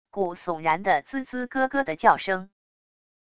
骨悚然的吱吱咯咯的叫声wav音频